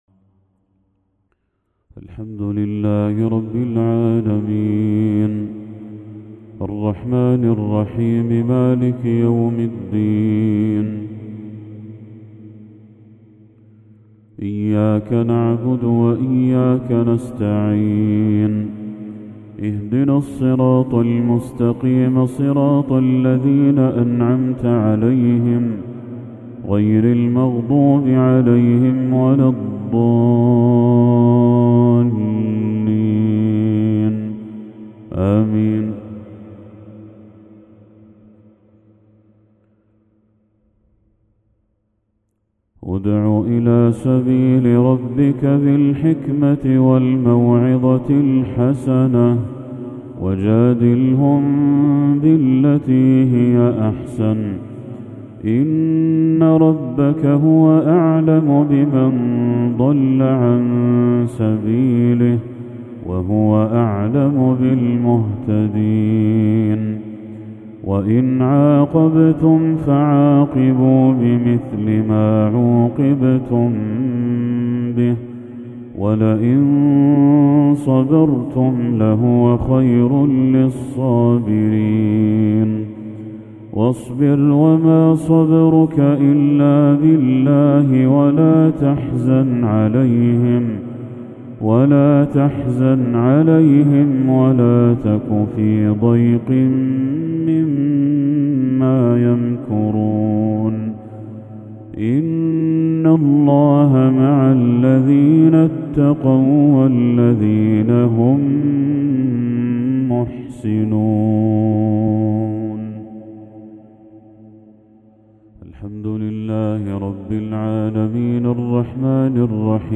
تلاوة بديعة للشيخ بدر التركي خواتيم سورتي النحل والذاريات | مغرب 1 ذو الحجة 1445هـ > 1445هـ > تلاوات الشيخ بدر التركي > المزيد - تلاوات الحرمين